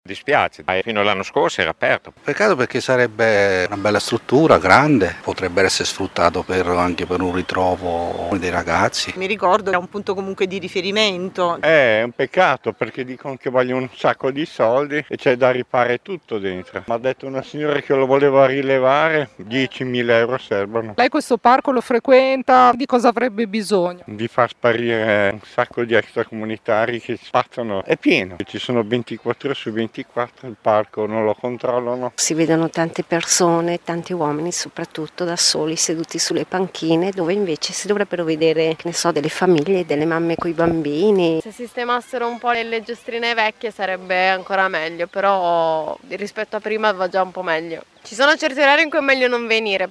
Qui sotto le interviste ad alcuni frequentatori dei Giardini…